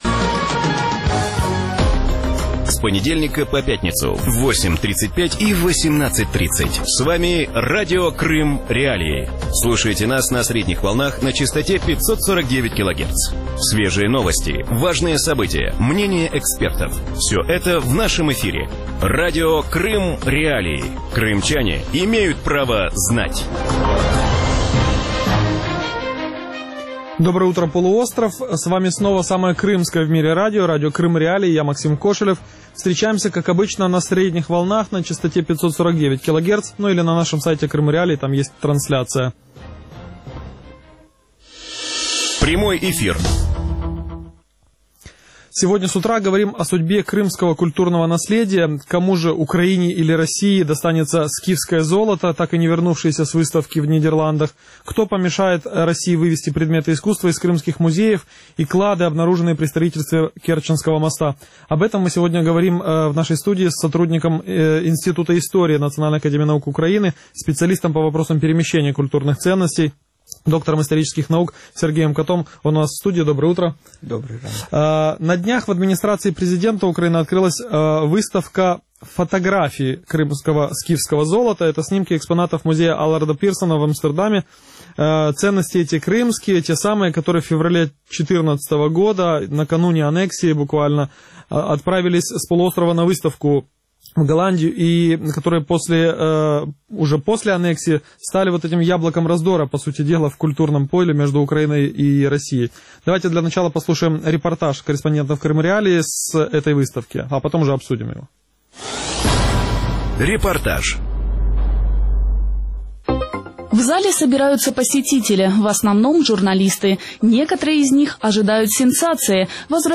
Утром в эфире Радио Крым.Реалии говорят о судьбе крымского культурного наследия.